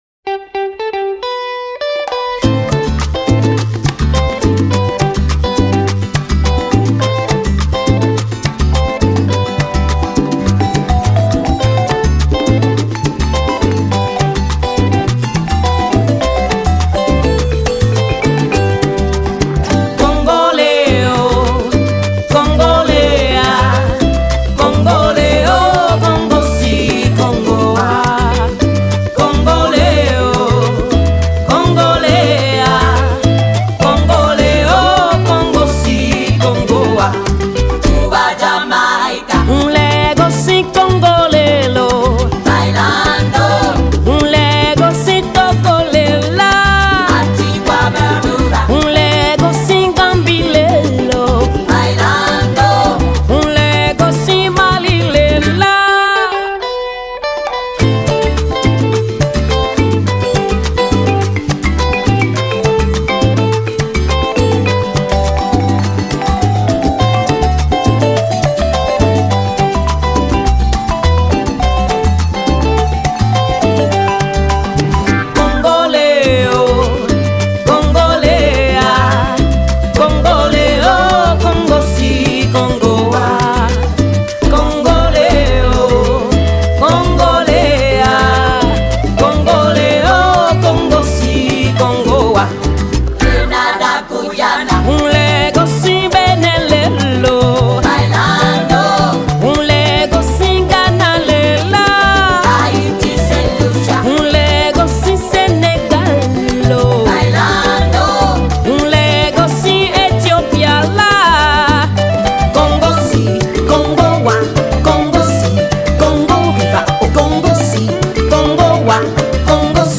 West African singer